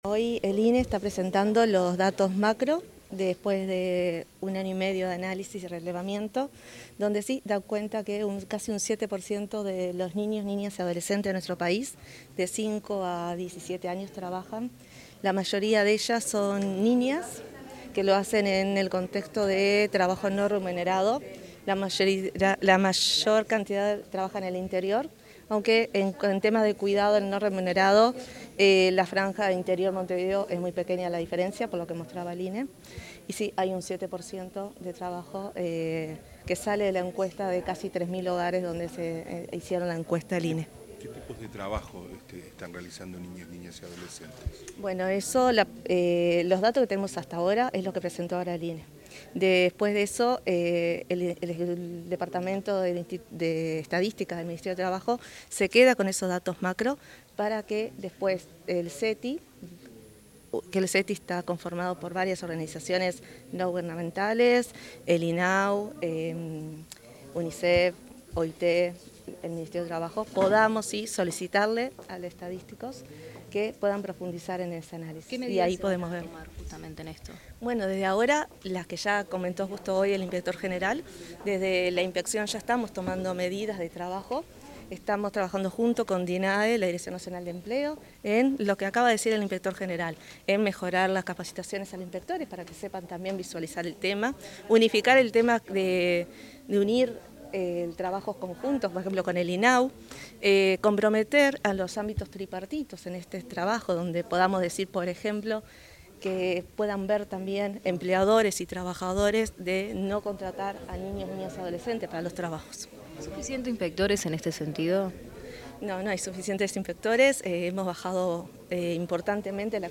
Declaraciones de la subinspectora general del Trabajo y la Seguridad Social, Andrea Bouret
Declaraciones de la subinspectora general del Trabajo y la Seguridad Social, Andrea Bouret 08/09/2025 Compartir Facebook X Copiar enlace WhatsApp LinkedIn La subinspectora general del Trabajo y la Seguridad Social, Andrea Bouret, realizó declaraciones durante la presentación de los resultados de la Encuesta Nacional sobre las Actividades de Niñas, Niños y Adolescentes 2024.